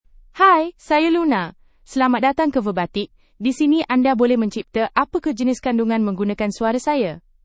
FemaleMalayalam (India)
Luna is a female AI voice for Malayalam (India).
Voice sample
Listen to Luna's female Malayalam voice.
Luna delivers clear pronunciation with authentic India Malayalam intonation, making your content sound professionally produced.